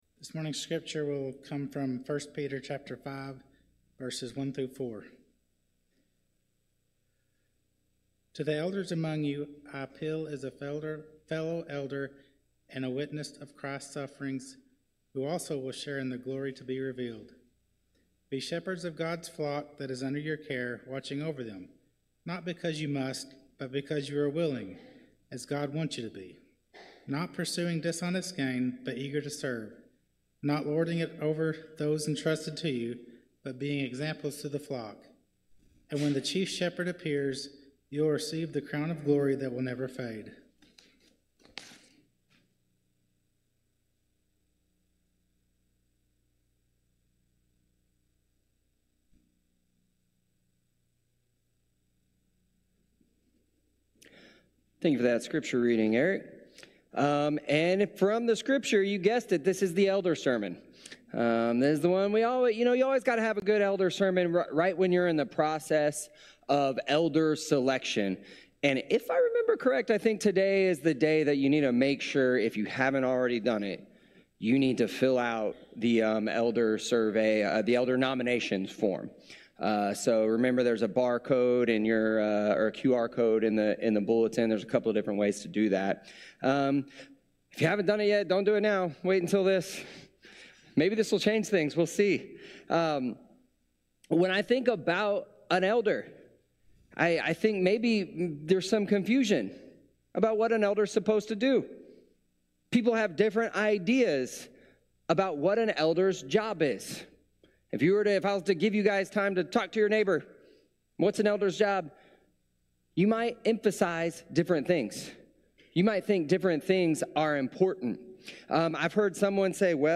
For the full service, watch the Livestream here